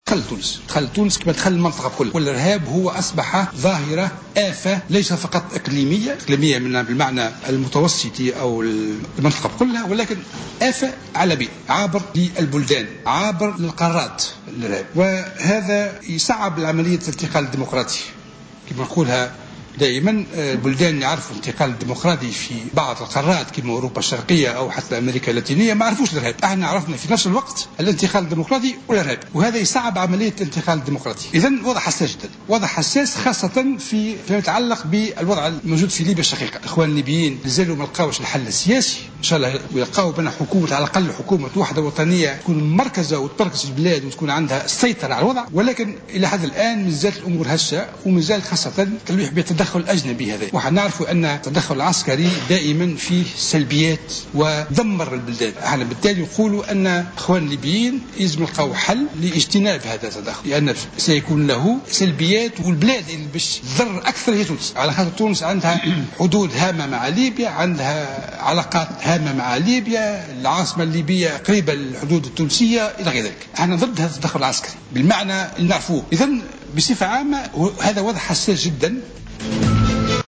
وأضاف الحرشاني اليوم خلال الاستماع إليه ضمن أشغال لجنة الأمن و الدفاع الإصلاح الإداري أن التدخل العسكري الأجنبي في ليبيا ستكون انعكاساته سلبية، داعيا الفرقاء الليبيين إلى ضرورة إيجاد حل لتجنب هذا الوضع والتوصّل إلى حل سياسي.